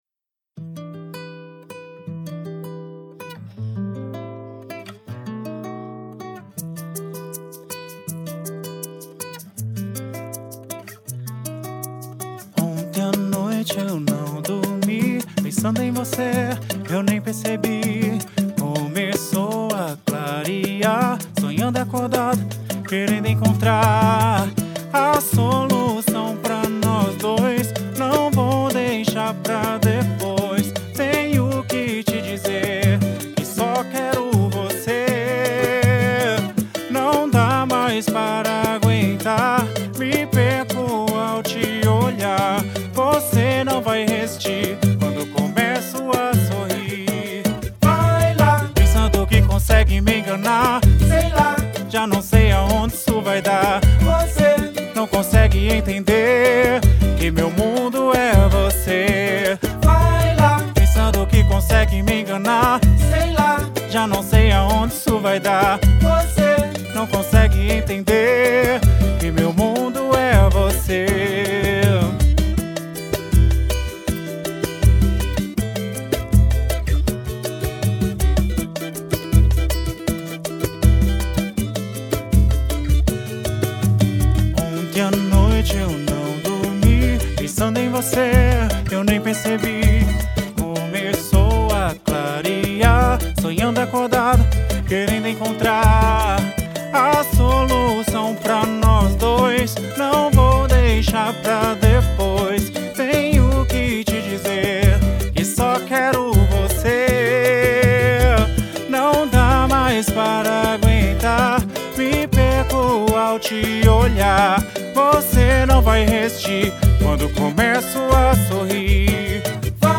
EstiloPagode